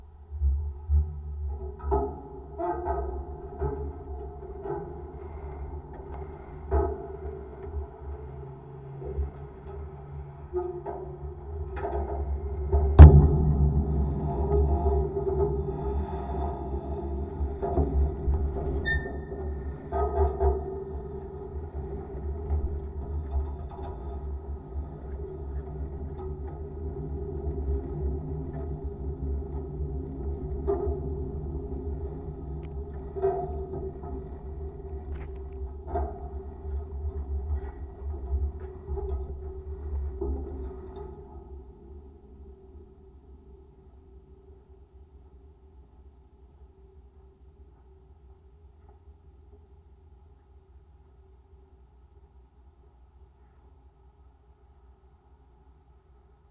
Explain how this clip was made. So the testing of listening devices that will be on board EAR1 begins. here is the first test. first-sound-test-rover.mp3